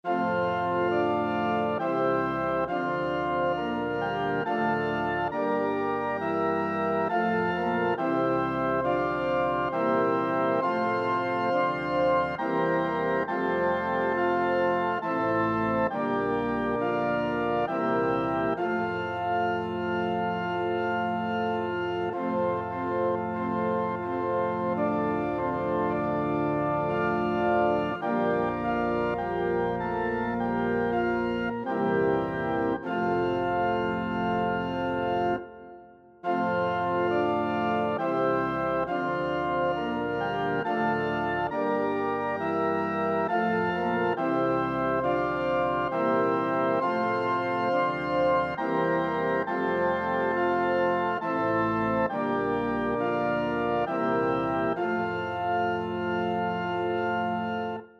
Alleluia--Novus-Ordo--Hyfrydol.mp3